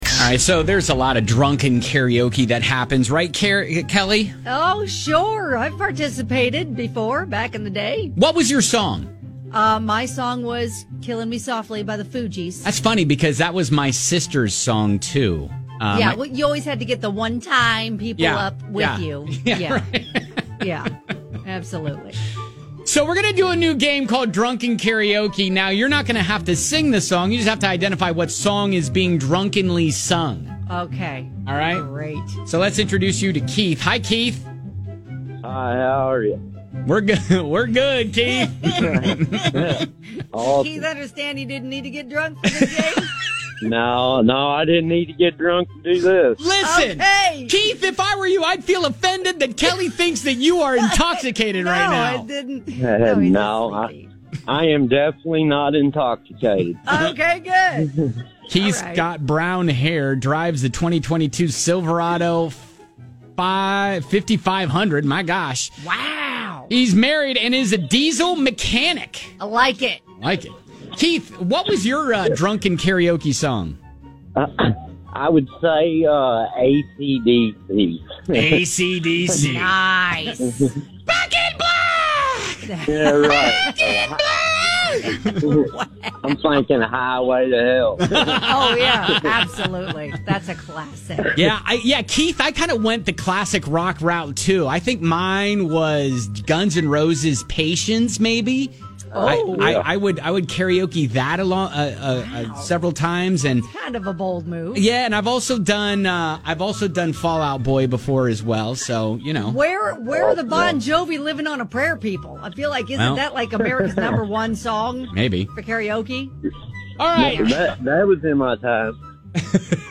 Can YOU identify the song being drunkenly sung in this "Drunk Karaoke" game?!?